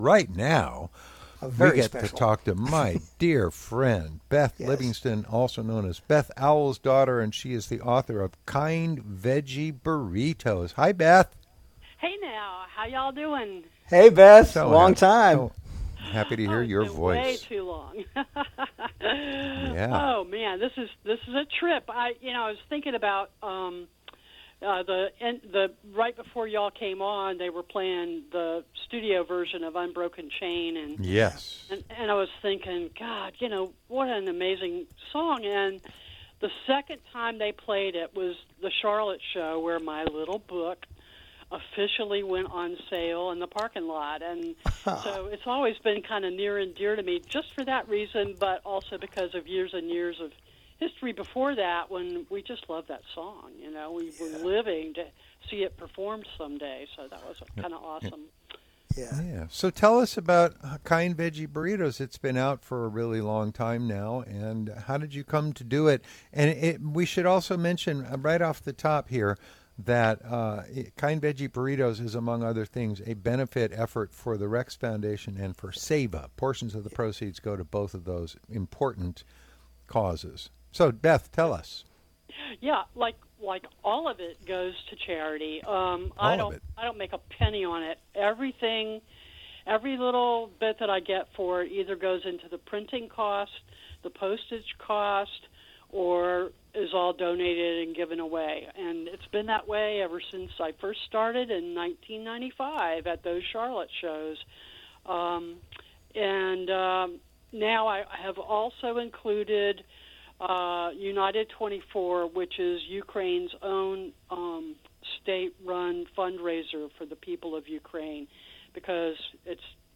Here’s our chat: